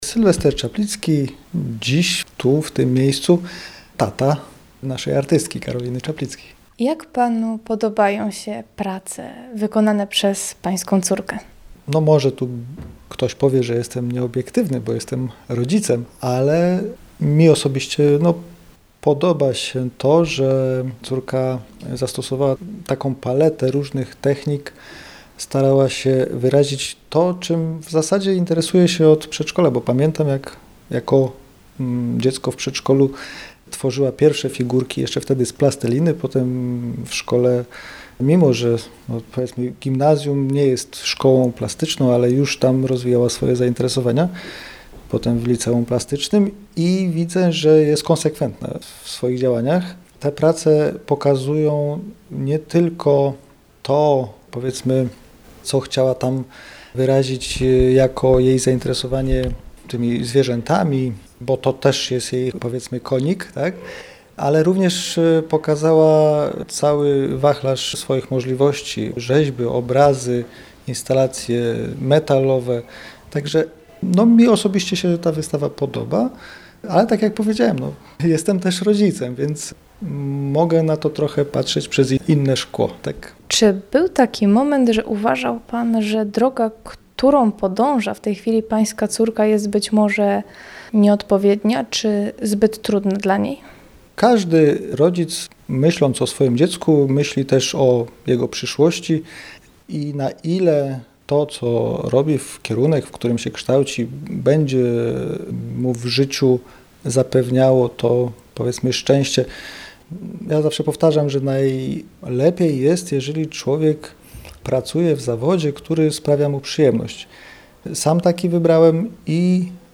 Wernisaż odbył się we wtorek 6 maja.